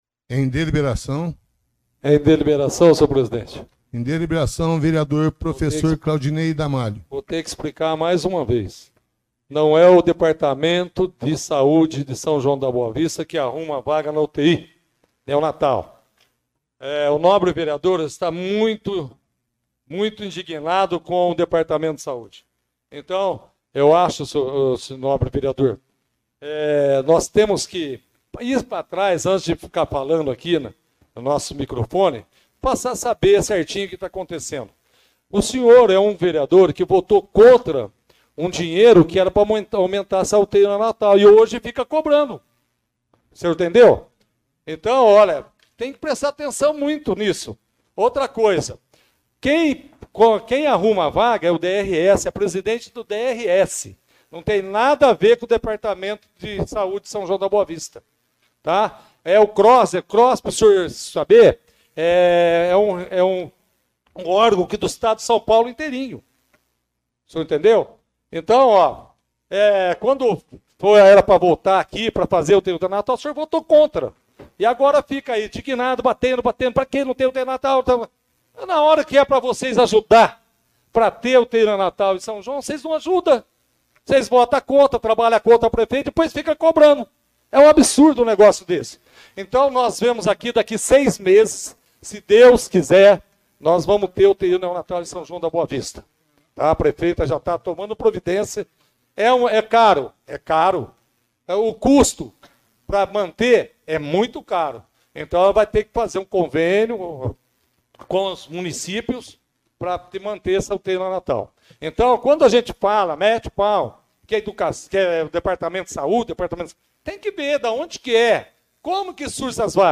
Ouça o pronunciamento de Claudinei Damálio na íntegra: